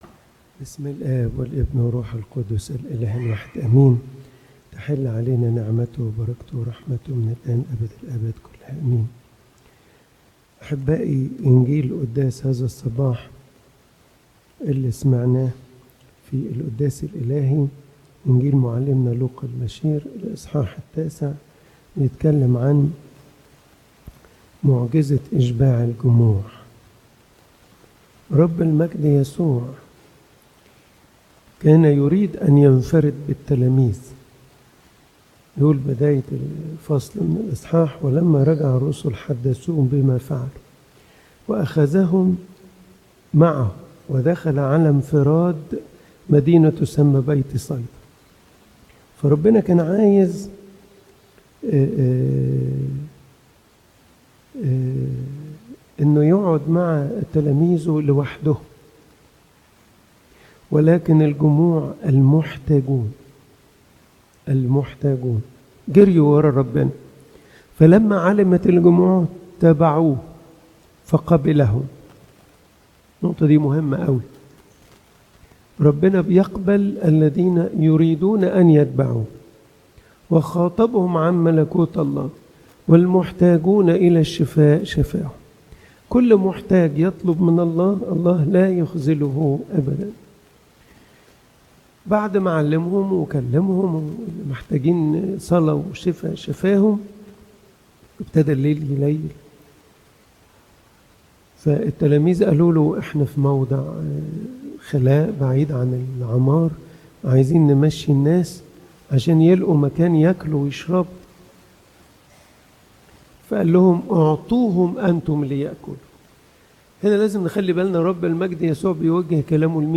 Content Type: Sermon